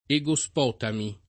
Egospotamo [ e g o S p 0 tamo ] top. m. stor. (od. Turchia)